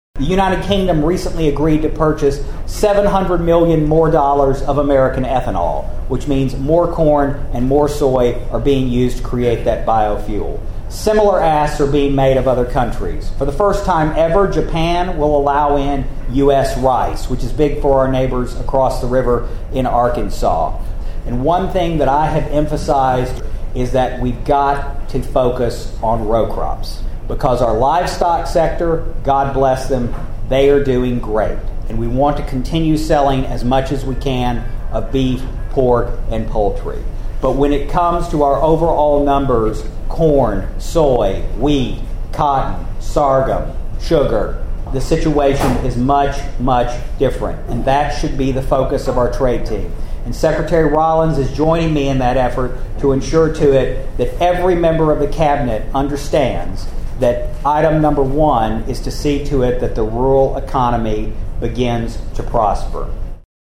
The Obion County Fair hosted their annual Farmers Harvest Breakfast on Thursday morning.
The featured guest speaker at the breakfast was Obion County’s Stephen Vaden, who is now the United States Deputy Secretary of Agriculture.